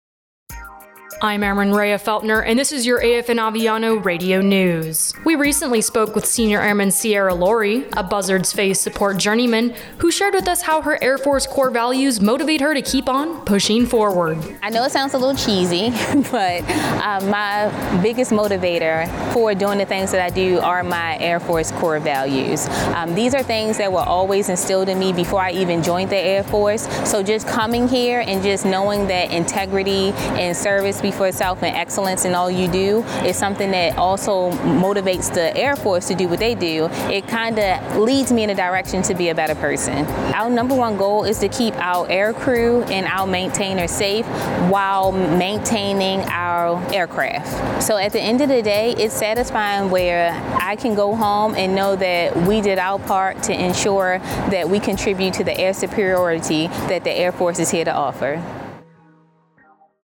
U.S. Air Force Radio News